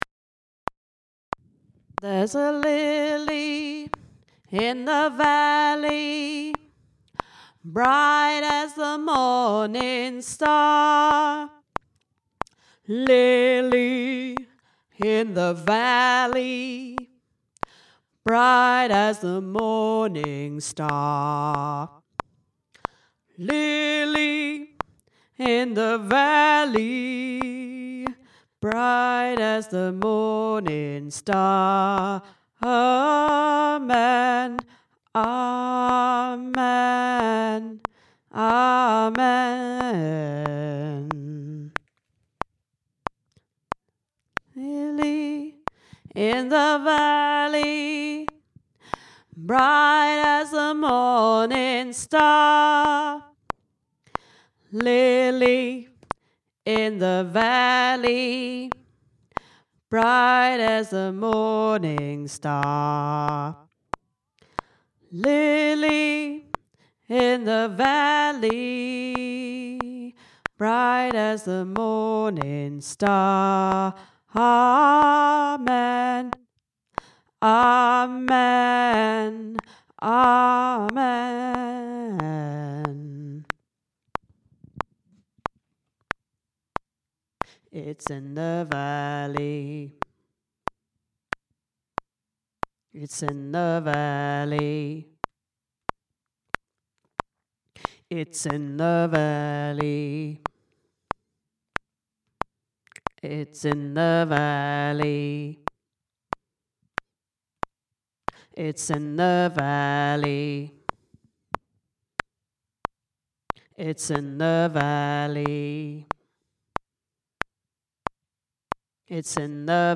LilyValley-Bass.mp3